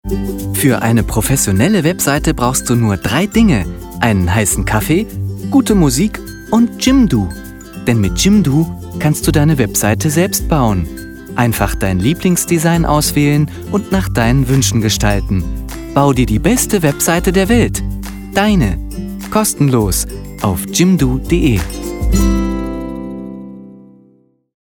Meine Stimme klingt warm, präzise, jung, klar und facettenreich.
Sprechprobe: Industrie (Muttersprache):
Young age warm sounding, friendly and fresh voice.